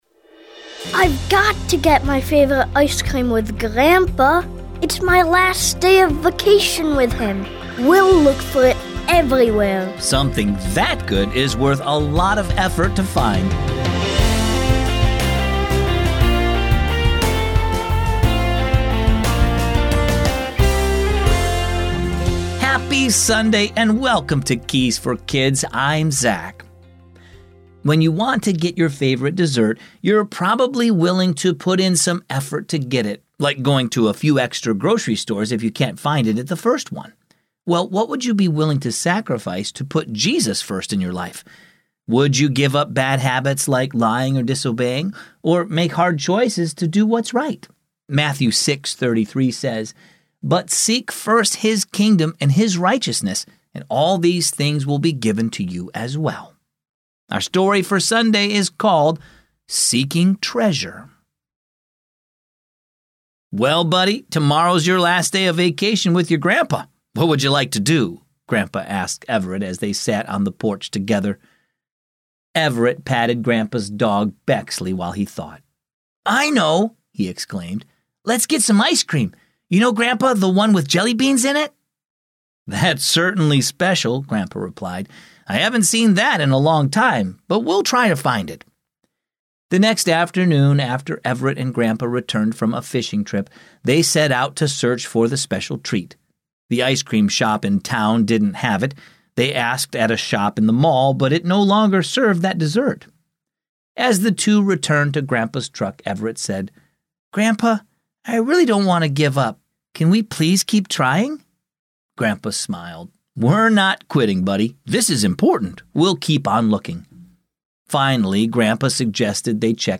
Keys for Kids is a daily storytelling show based on the Keys for Kids children's devotional.